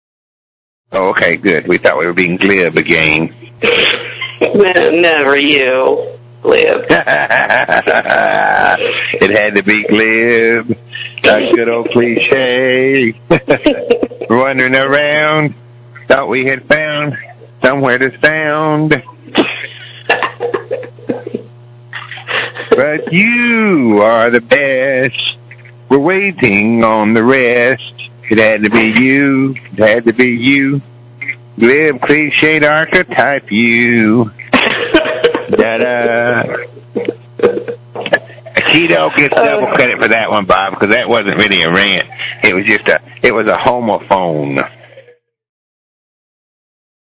rant